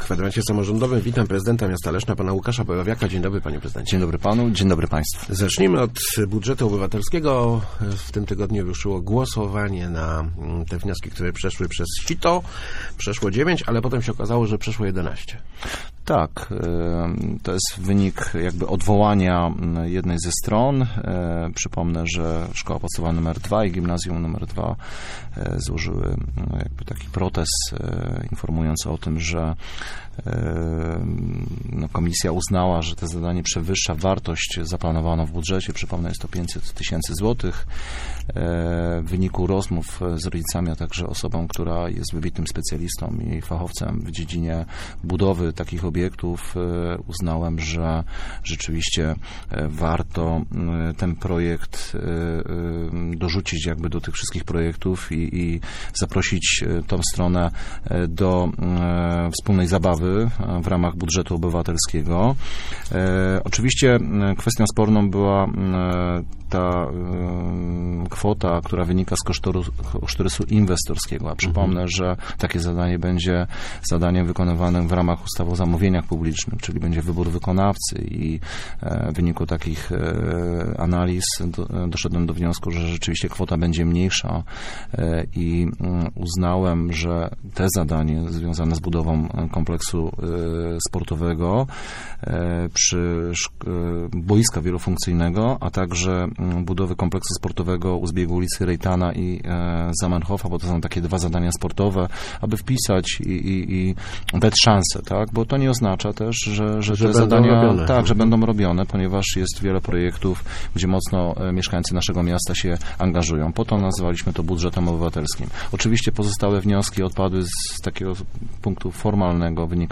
Gościem Kwadransa był prezydent Łukasz Borowiak.